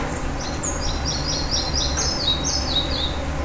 малая мухоловка, Ficedula parva
СтатусСлышен голос, крики